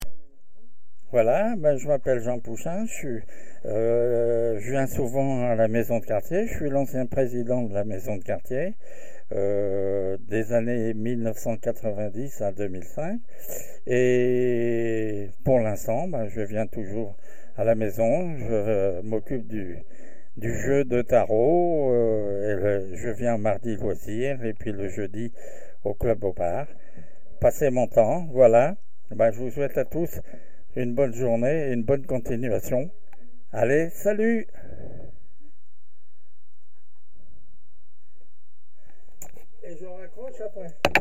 Cabine de témoignages
Témoignage du 5 juin 2025 à 17h30